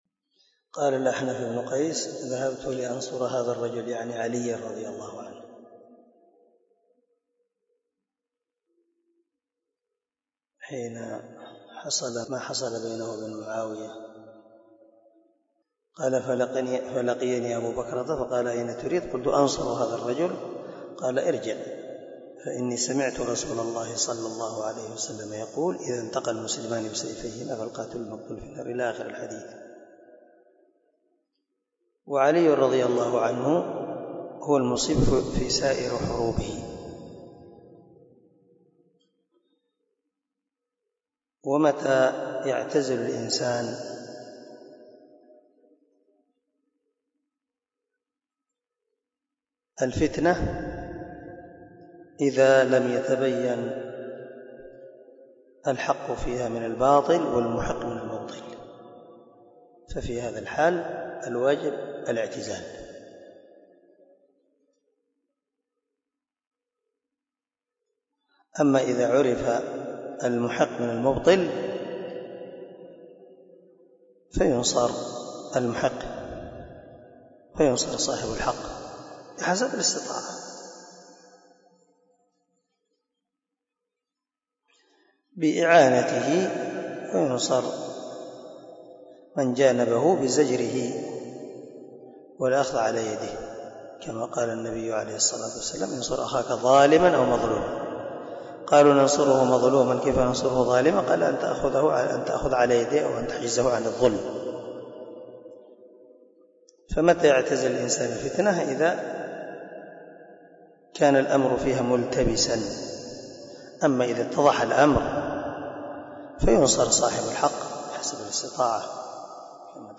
033الدرس 23 من شرح كتاب الإيمان حديث رقم ( 32 ) من صحيح البخاري